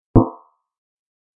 Fourier's Mojo " 小心逆转点击声
Tag: 点击 注意 倒车 三倍频 声音